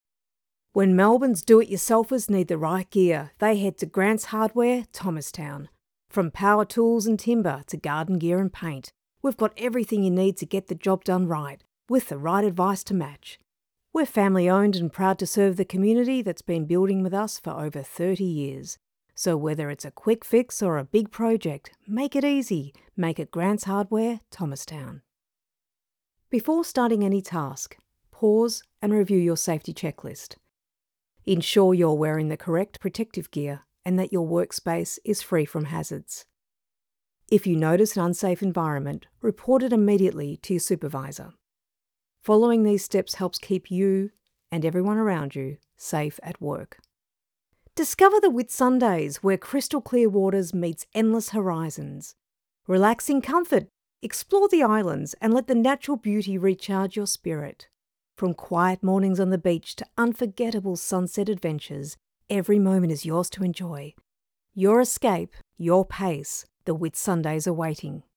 Foreign & British Female Voice Over Artists & Actors
Adult (30-50)